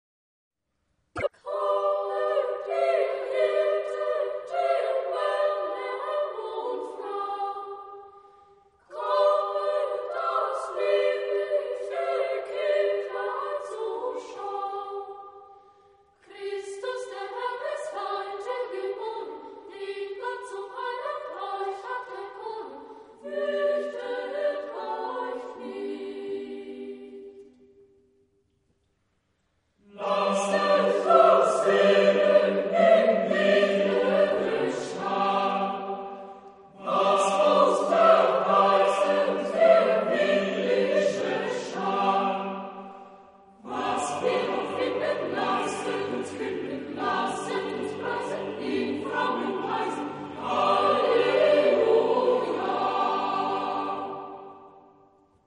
Genre-Style-Forme : Folklore ; Chanson ; Sacré
Type de choeur : SSAATTBB  (8 voix mixtes )
Tonalité : sol majeur